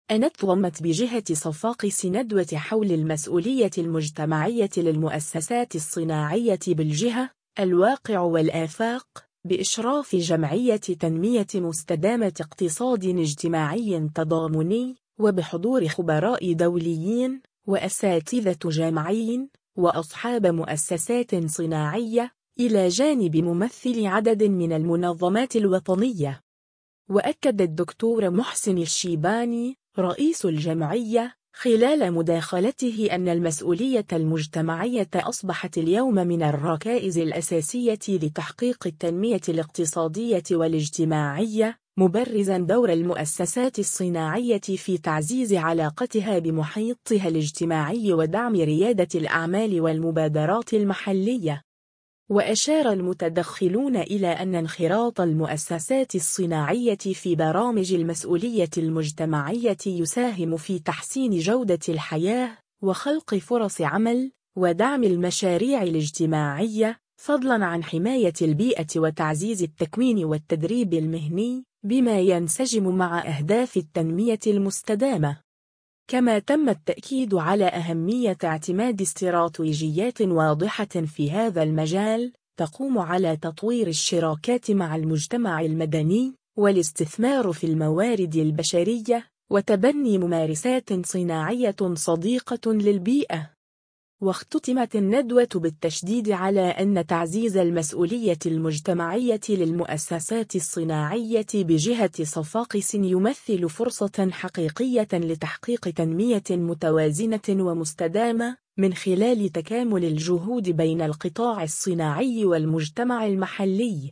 صفاقس: ندوة حول المسؤولية المجتمعية للمؤسسات الصناعية بالجهة: الواقع والآفاق [فيديو]